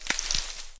step_grass.ogg